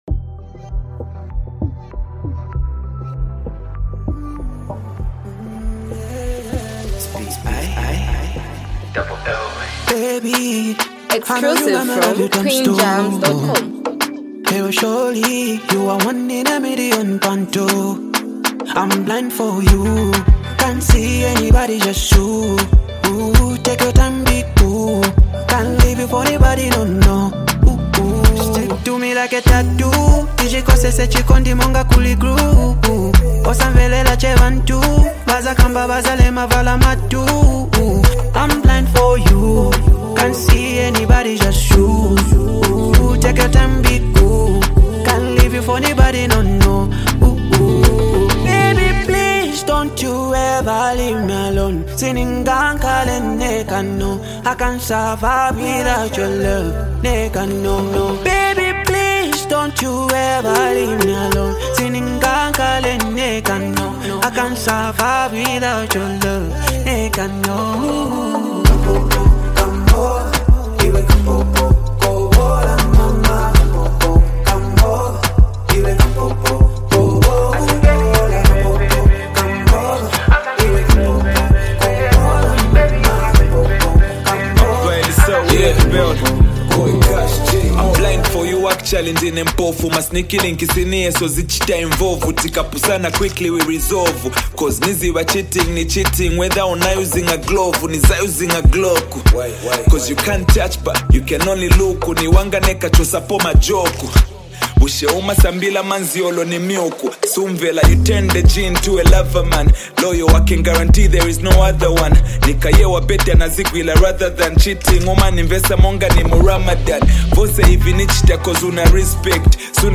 socially conscious street anthem
sharp rap flow and confident delivery